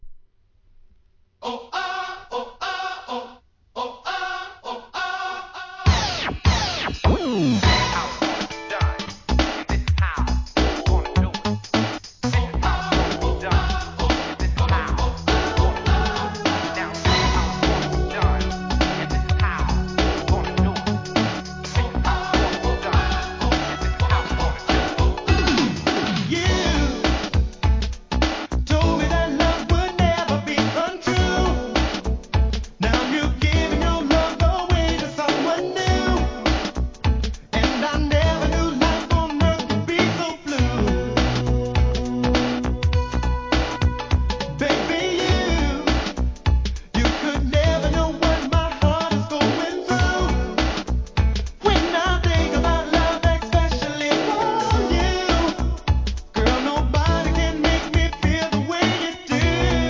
SOUL/FUNK/etc...
NEW JACK SWING〜SLOWまでバランス委の良い内容です！